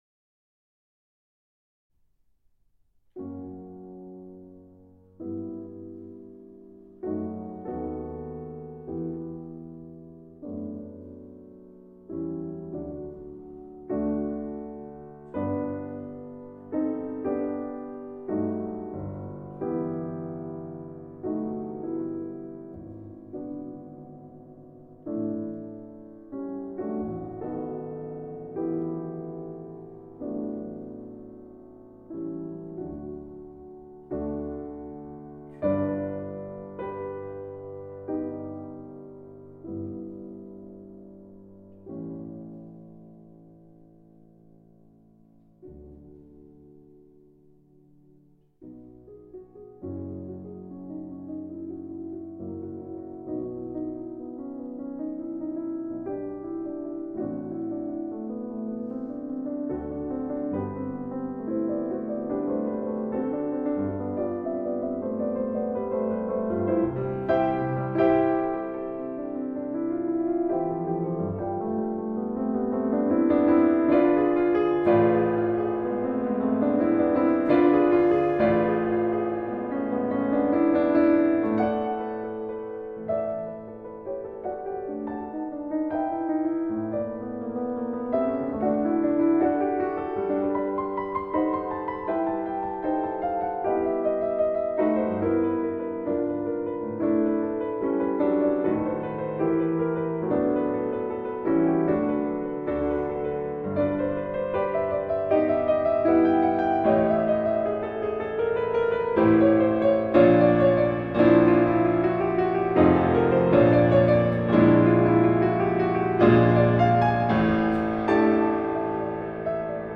La pianiste japonaise
Calme d’abord, puis plus animé, ré majeur